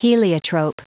Prononciation du mot heliotrope en anglais (fichier audio)
Prononciation du mot : heliotrope